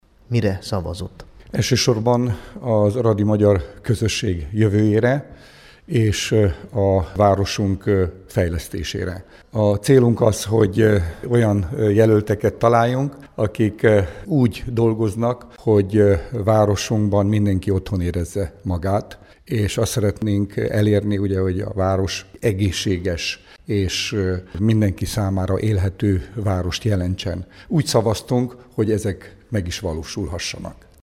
Hallgassa meg Bognár Levente nyilatkozatát: